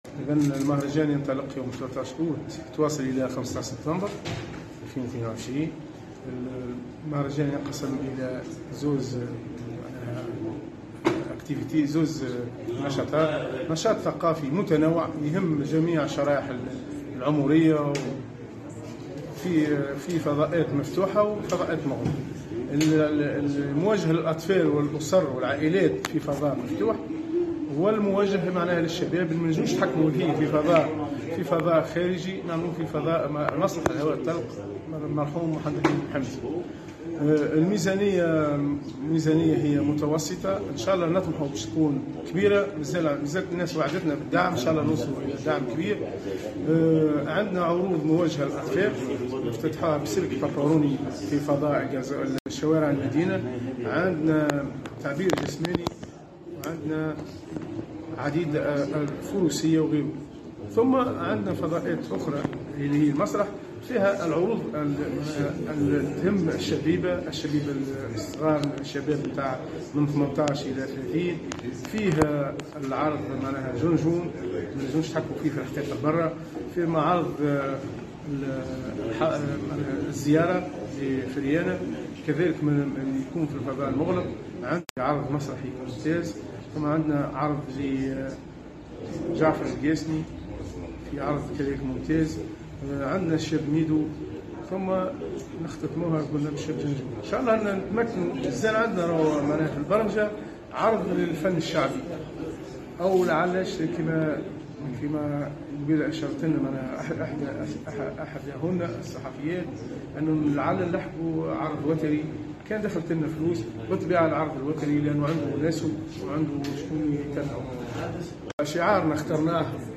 برمجة الدورة 42 للمهرجان الدولي للتفاح بسبيبة (تصريح)